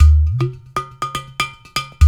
120 -UDU 09L.wav